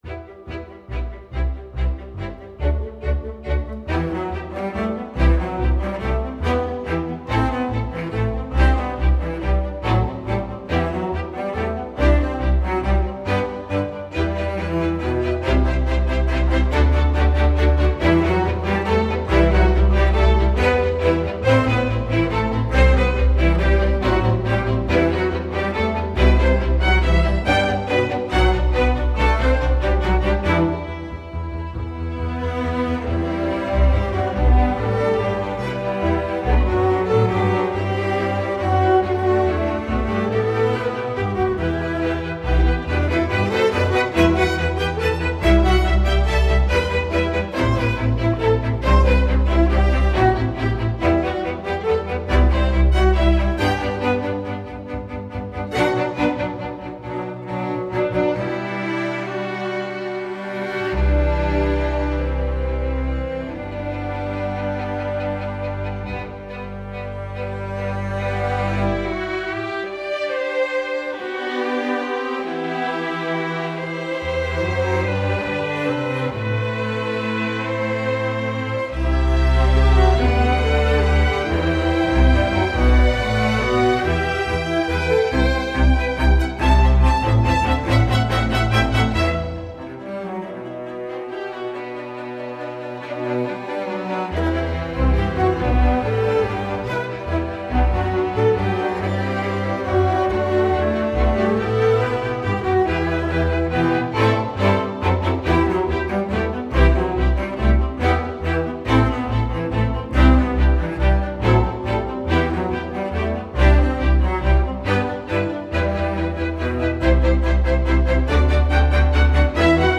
dynamic piece in E minor
String Orchestra